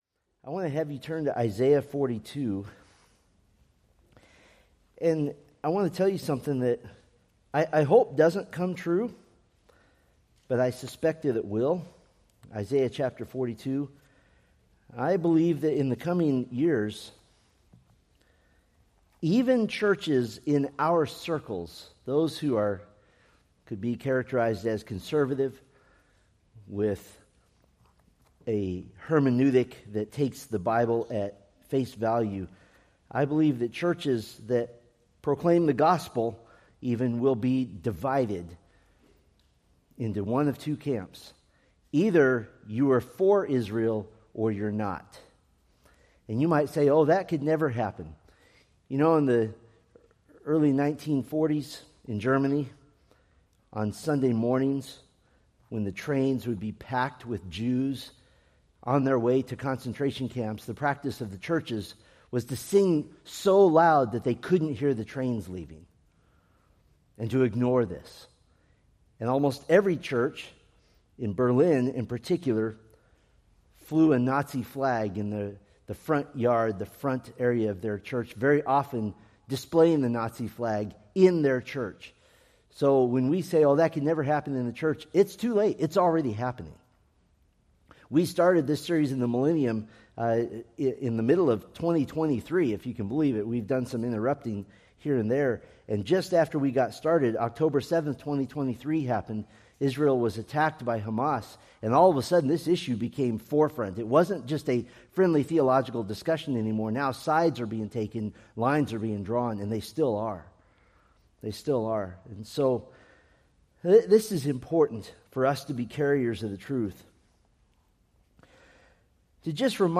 Preached November 9, 2025 from Selected Scriptures